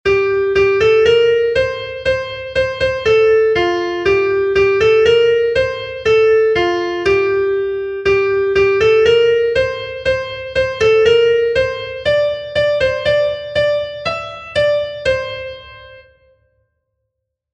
Kontakizunezkoa
Lauko handia (hg) / Bi puntuko handia (ip)
A-A2